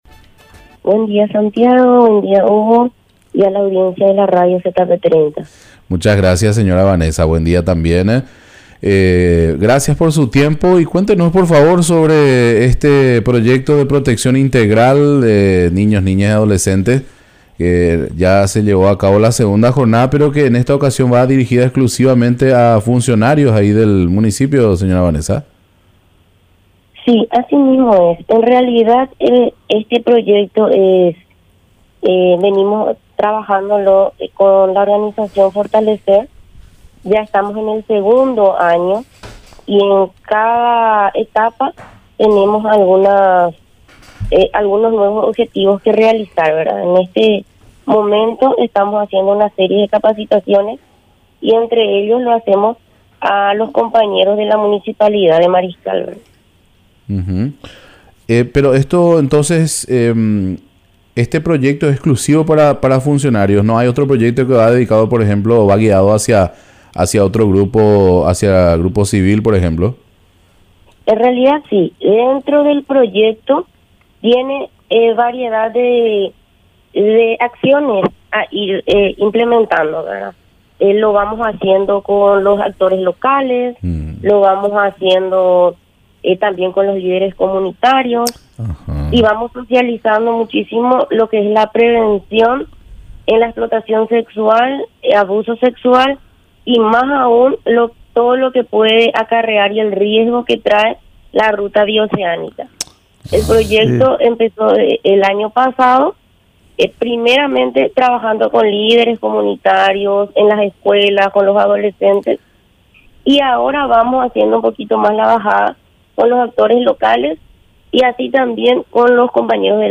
Entrevistas / Matinal 610